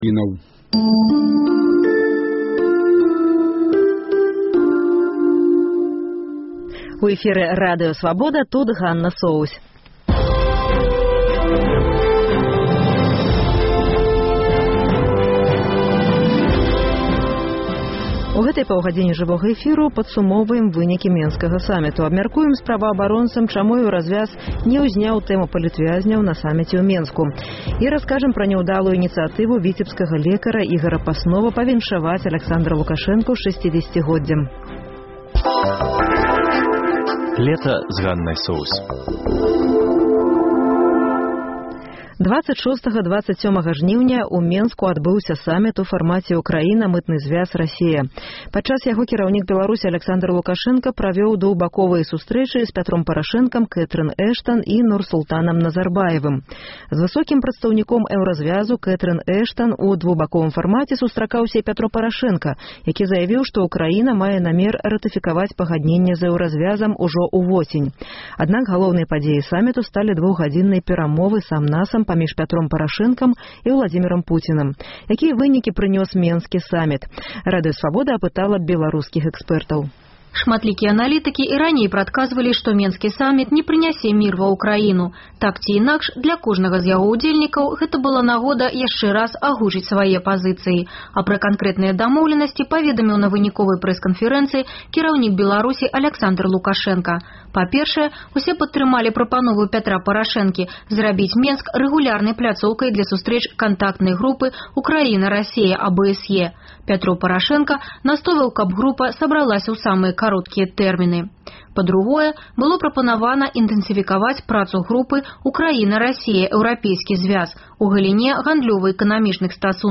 Рэпартаж Свабоды.